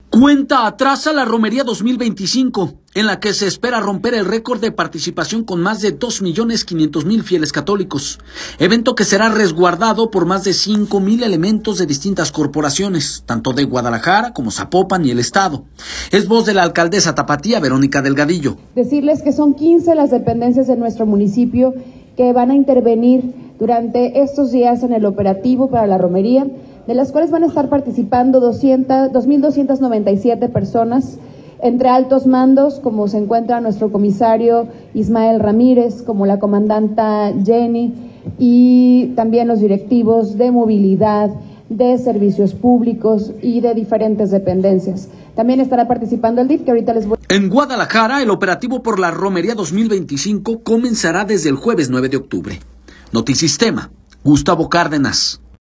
Cuenta regresiva a la Romería 2025, en la que se espera romper récord de participación con más de 2 millones 500 mil fieles católicos, evento será resguardado por más de 5 mil elementos de distintas corporaciones, tanto de Guadalajara, como de Zapopan y del Estado, es voz de la alcaldesa tapatía, Verónica Delgadillo.